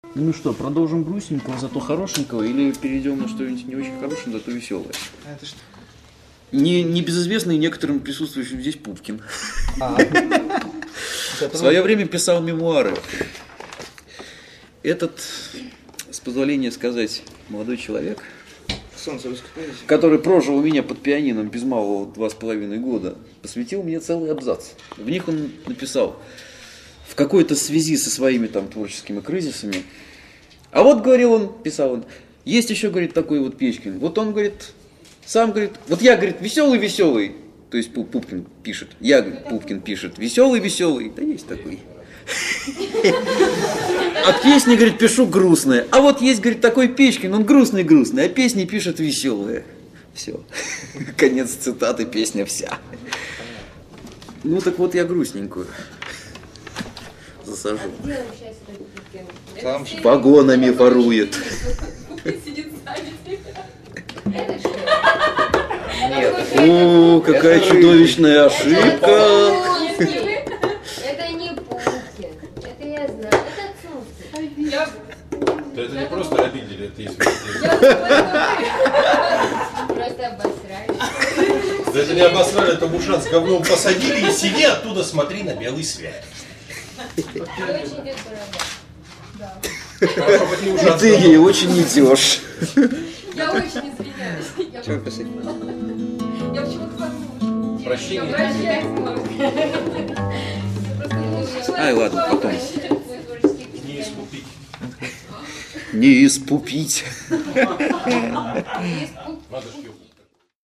Квартирный концерт
Интермедия 12: исторически-мемуарная, с исправлением опасных заблуждений (1771 Kb)